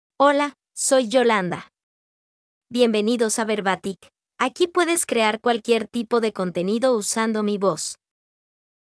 FemaleSpanish (Nicaragua)
Yolanda is a female AI voice for Spanish (Nicaragua).
Voice sample
Female
Yolanda delivers clear pronunciation with authentic Nicaragua Spanish intonation, making your content sound professionally produced.